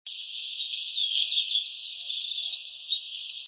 Lincoln's Sparrow
Lincoln's Sparrow: this sound was recorded at 7,200' next to a brushy talus slope leading down to the Merced River at 4,000'. I believe it to be the song of the Lincoln's Sparrow although I was unable to observe the bird.  6/5/03 (14kb)
sparrow_lincolns_possibly_789.wav